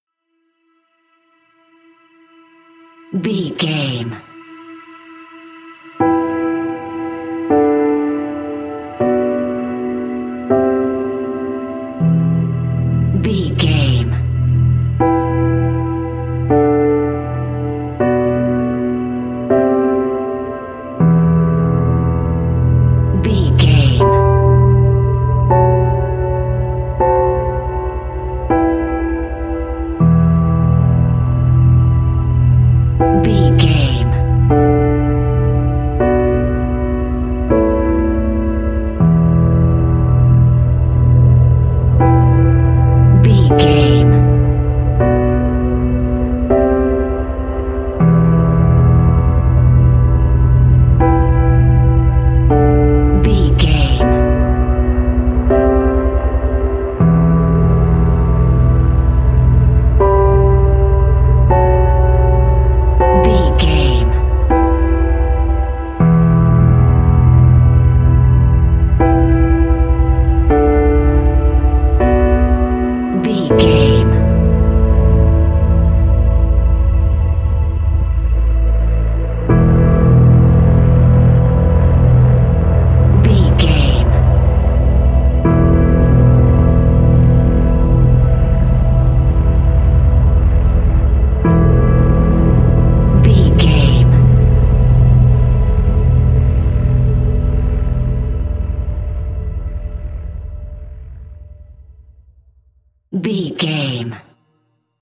Very tense and dark composition.
Thriller
Aeolian/Minor
Fast
dark
dramatic
hypnotic
synthesiser
bass guitar
piano
mournful
melancholy